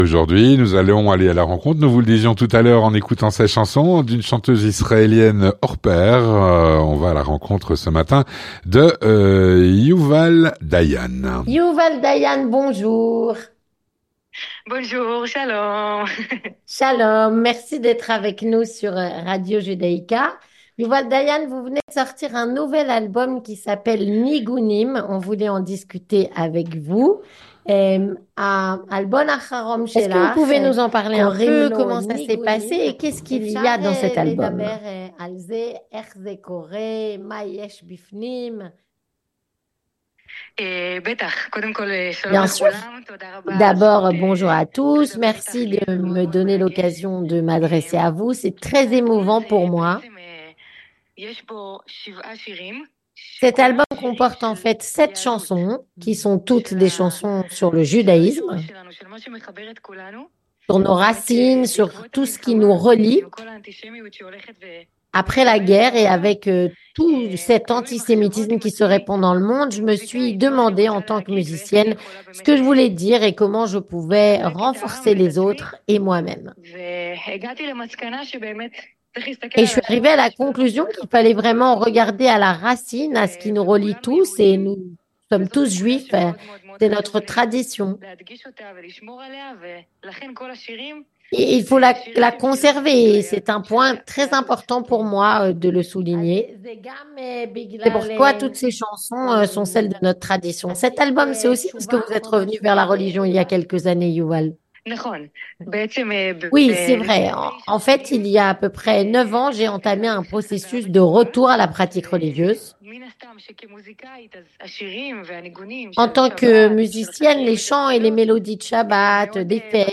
Rencontre - Yuval Dayan, chanteuse israélienne (29/02/2024)
Yuval Dayan est une chanteuse israélienne extrêmement connue. Son parcours est atypique. Elle en parle au micro de Radio Judaïca.